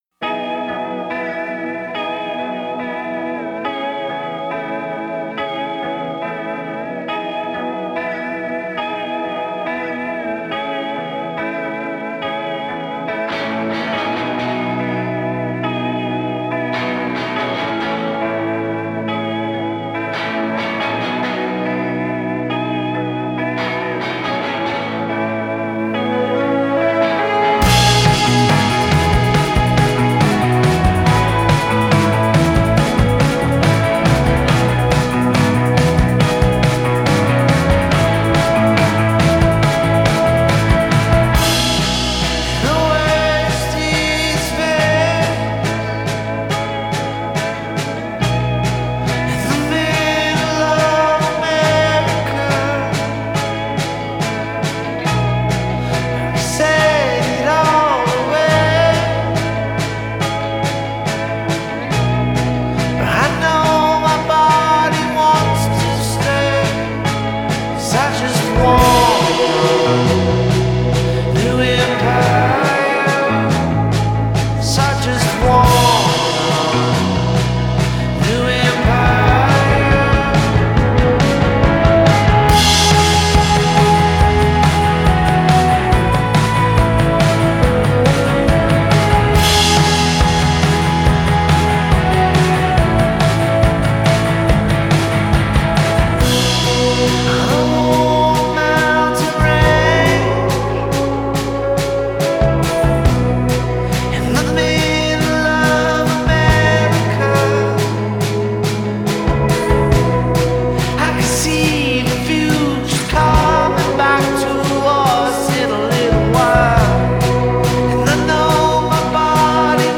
A warbling synth kicks off the expansive